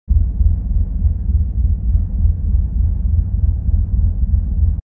rumble_trading_depot.wav